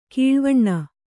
♪ kīḷvaṇṇa